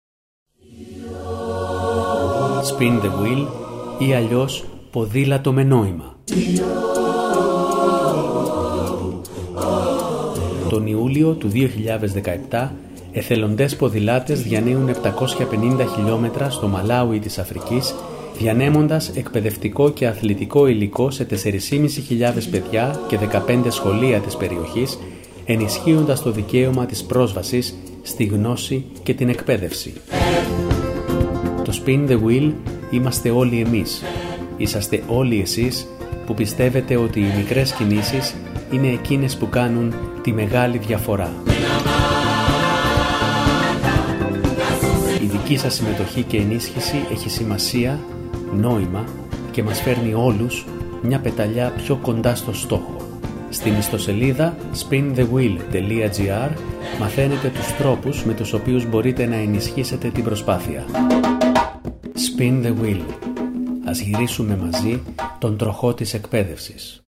SPIN-THE-WHEEL-FINAL-RADIO-SPOT-NEST-SP.mp3